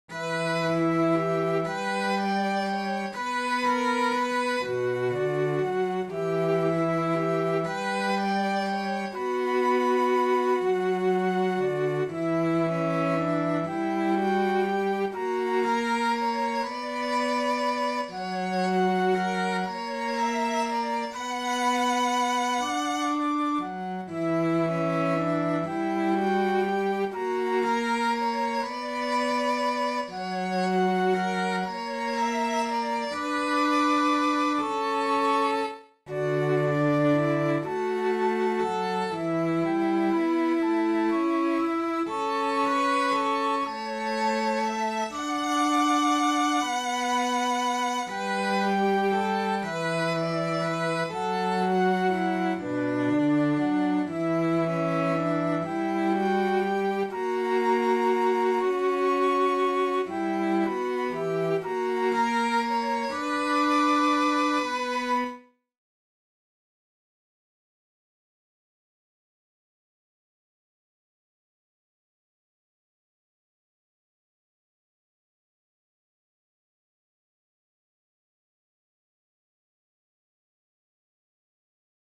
Turvallinen-matka-huilu-ja-sellot.mp3